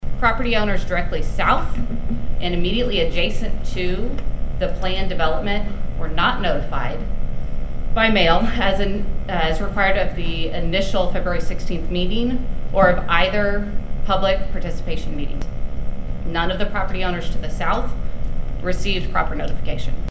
One concerned citizen who would be affected by the building of these structures shared why she is against this plan being passed.
Lady Opposing Agenda Item.mp3